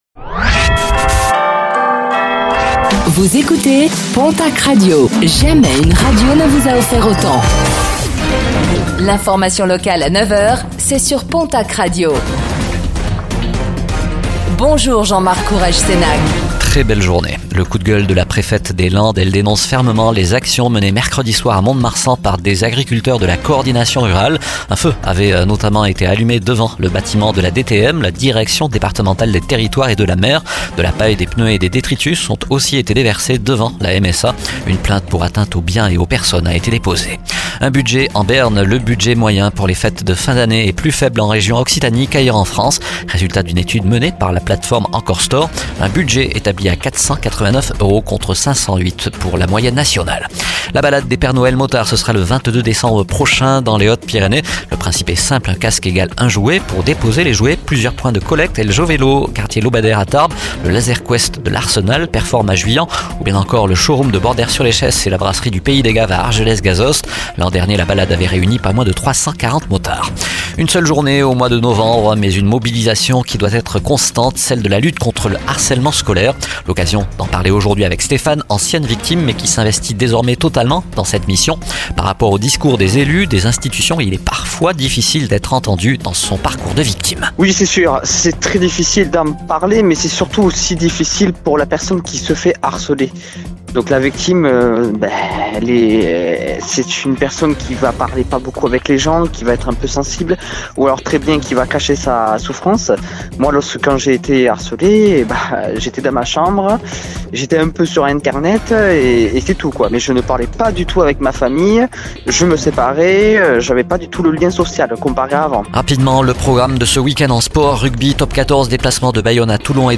09:05 Écouter le podcast Télécharger le podcast Réécoutez le flash d'information locale de ce vendredi 22 novembre 2024